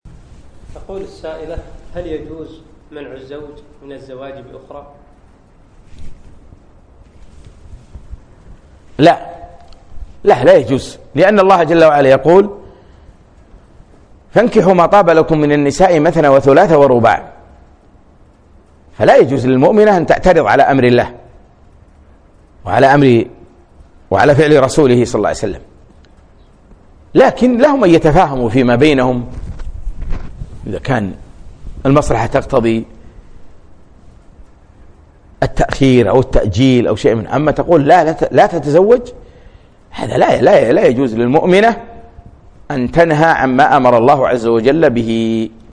من محاضرة وقفات من سورة النور أقيمت في مركز نعيمة الدبوس صباحي الاثنين 3 4 2017